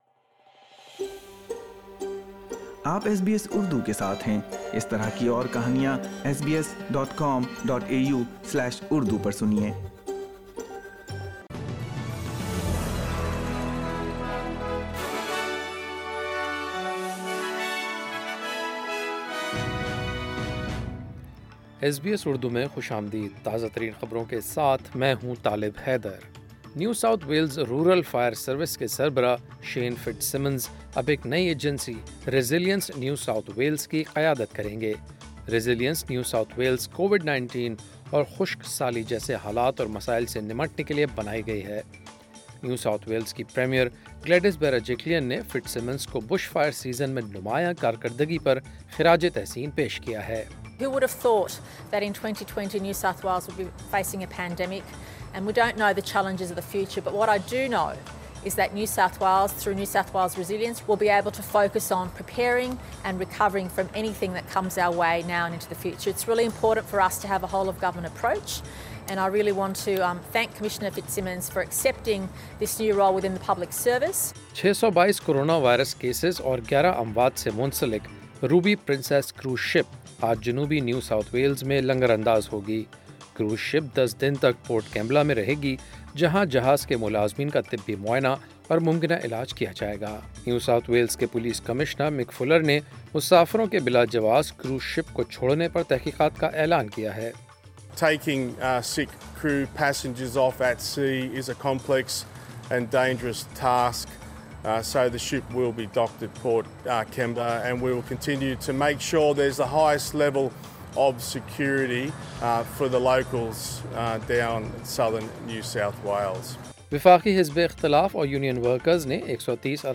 daily_news_6.4.20_3pm.mp3